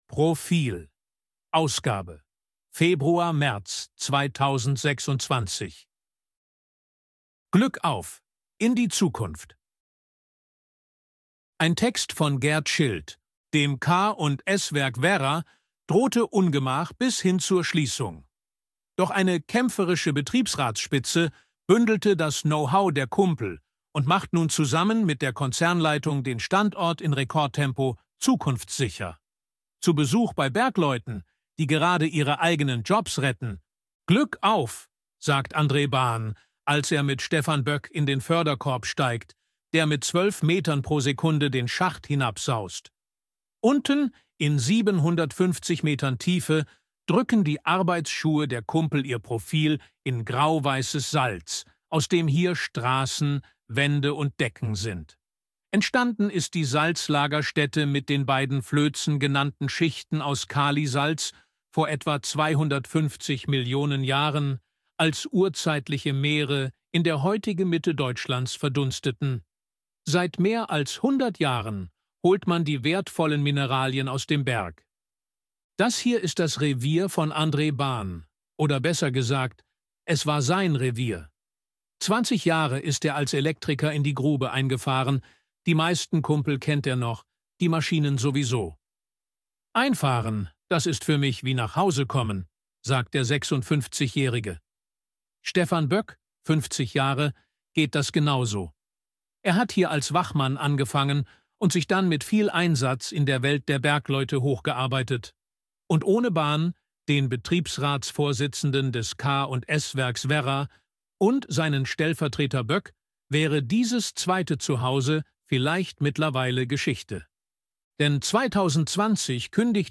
ElevenLabs_261_KI_Stimme_Mann_Reportage.ogg